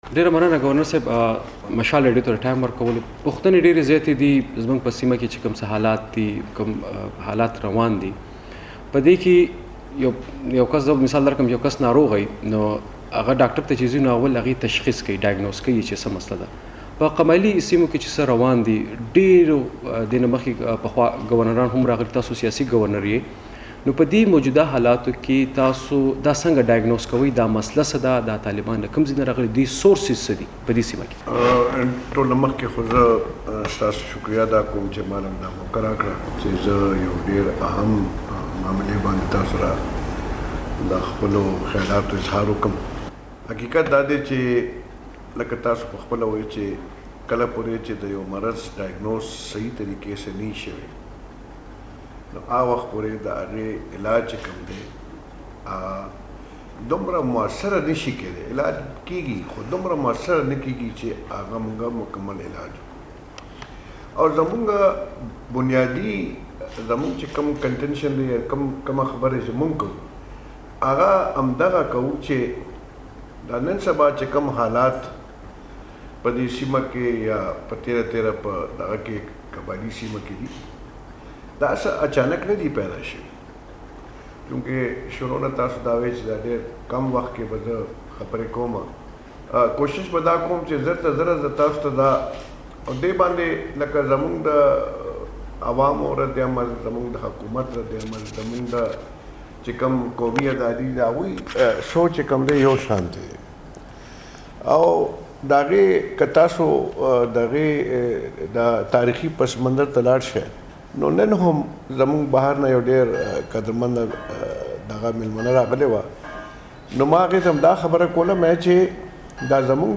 نوموړي دا خبرې د مشال ریډیو ځانګړې مرکه کې کولې